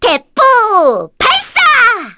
Miruru-Vox_Skill1_kr.wav